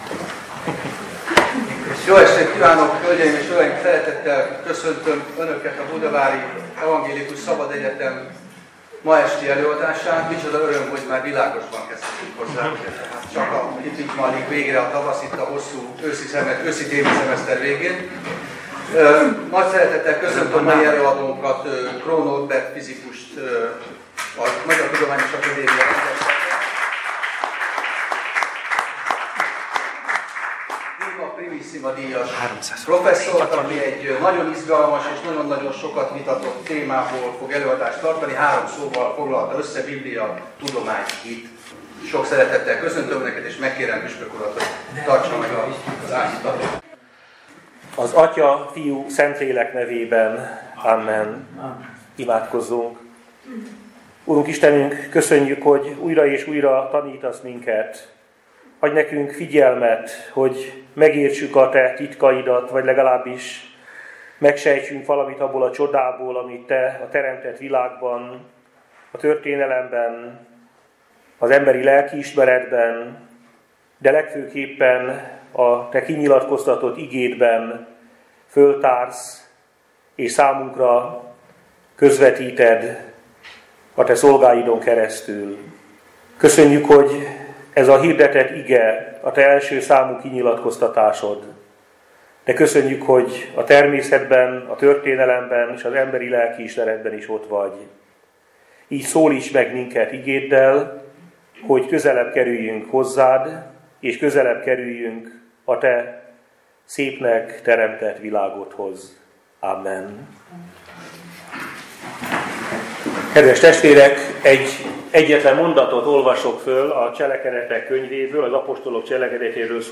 Így értékelte a hit és a tudományos gondolkodás közötti viszonyt Kroó Norbert akadémikus, a szilárdtestfizika Prima Primissima díjas professzora a budavári evangélikus szabadegyetem legutóbbi, április 9-i előadásán.
A Biblia – tudomány – hit címmel elhangzott előadás bevezetőjeként Fabiny Tamás püspök az Apostolok cselekedeteiből vett igére épülő áhítatában azt mondta, hogy a Szentírásban tájékozódó tudósok segítenek rendet tenni a bennünket körülvevő, gyakran nehezen követhető 21. századi világban.